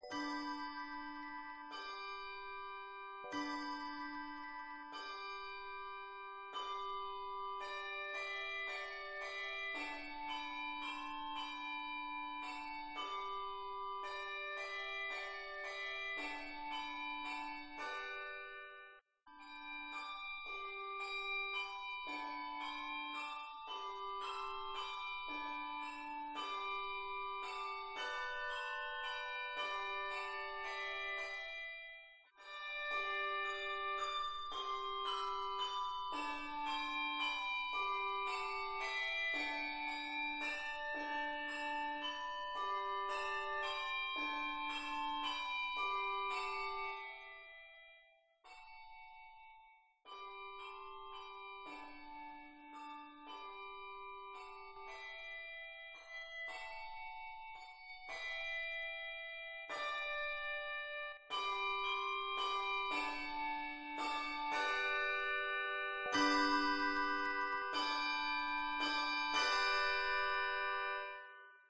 For handbells or handchimes, 3 octaves, 13 or 14 bells used.
Traditional French
Handbells/Handchimes 3 octaves, 13 or 14 bells used.
Christmas Christmas.